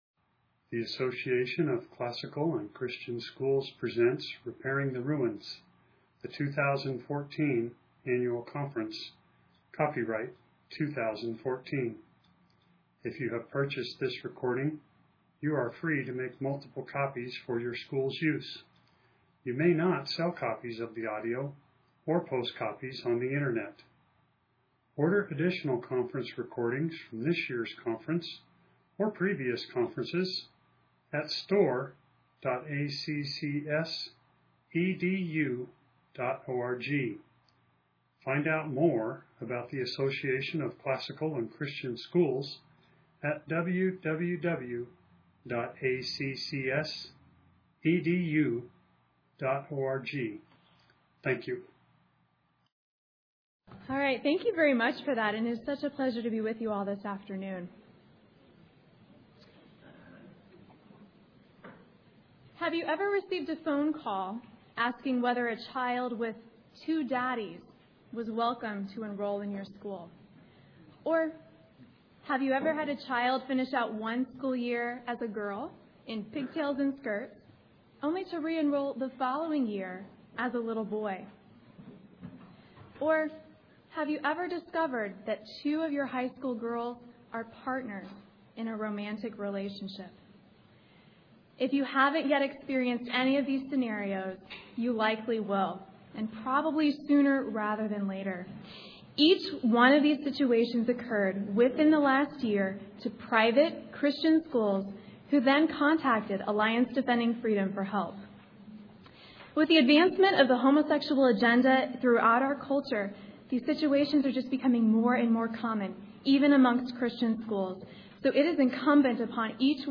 2014 Leaders Day Talk | 0:42:02 | Culture & Faith, Leadership & Strategic